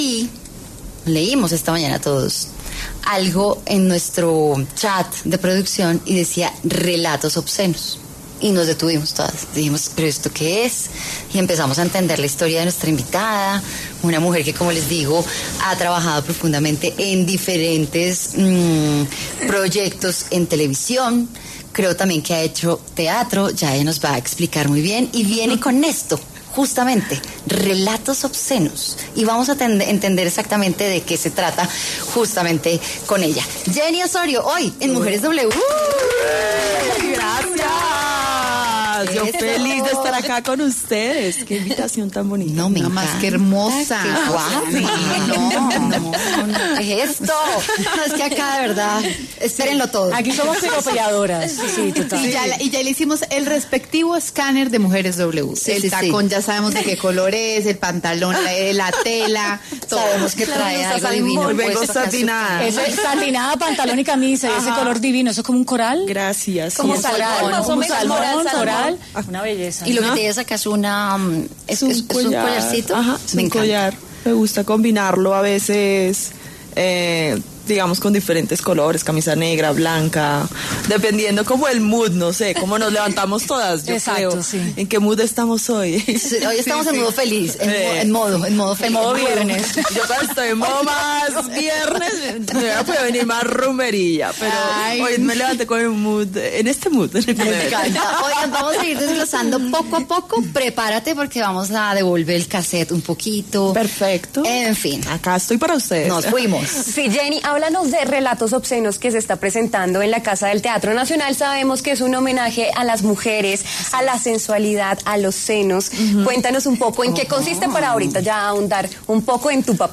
La reconocida actriz colombiana Jenny Osorio habló en Mujeres W sobre la nueva obra de teatro en la que participó: ‘Relatos Obscenos’.